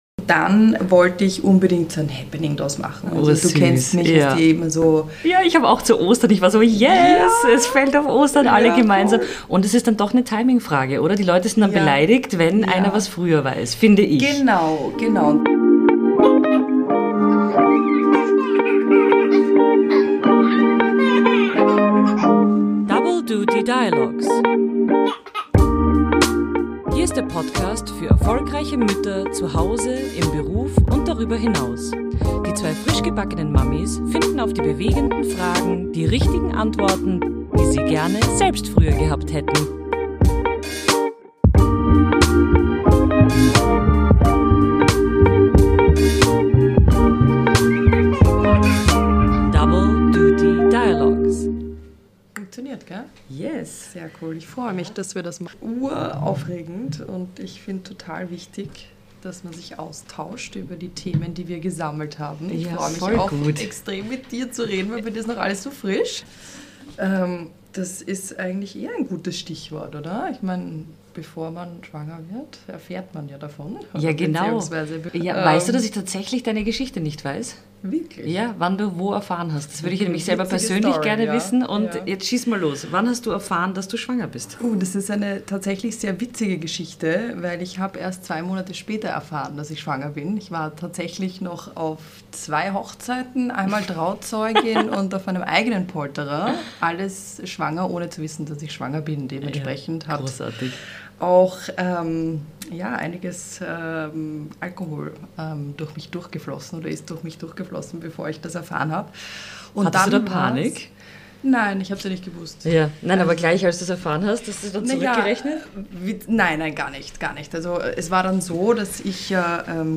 Achtung, es geht los und das mit einem Live-Launch, quasi einem Paukenschlag mit Mimosa und Business-Breakfast!